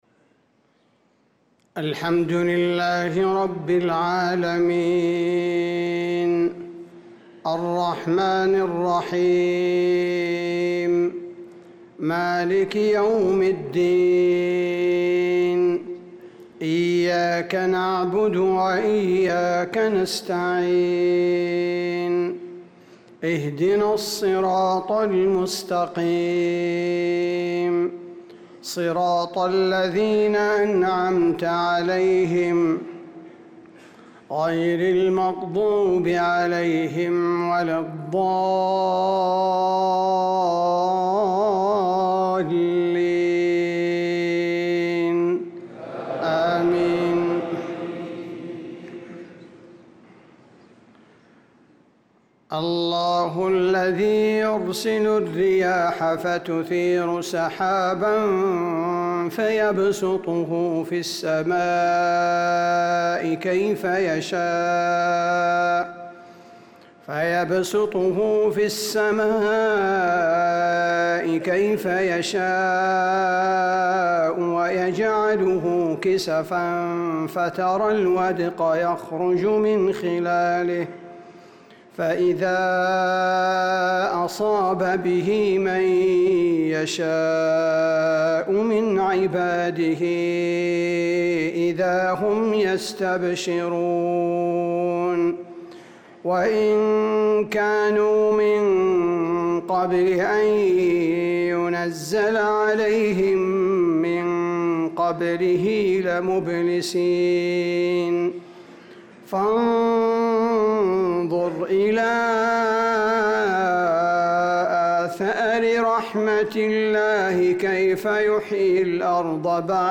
صلاة المغرب للقارئ عبدالباري الثبيتي 20 شوال 1445 هـ
تِلَاوَات الْحَرَمَيْن .